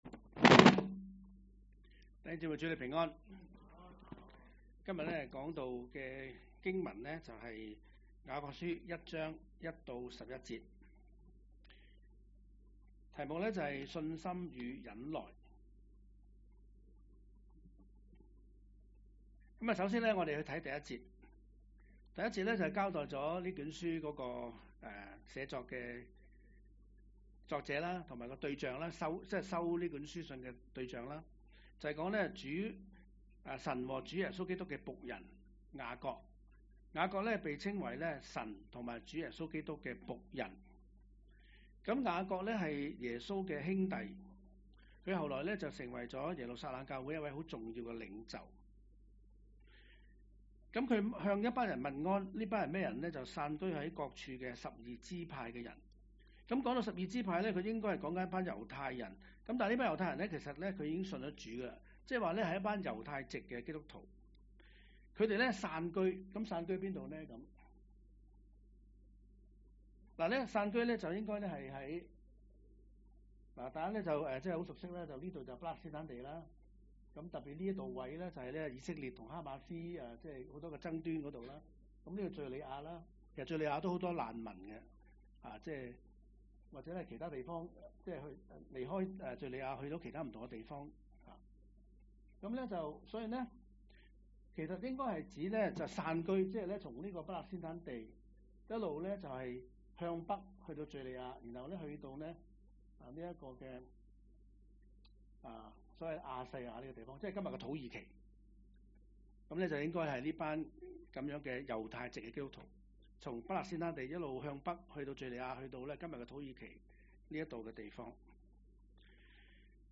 講道 ：信心與忍耐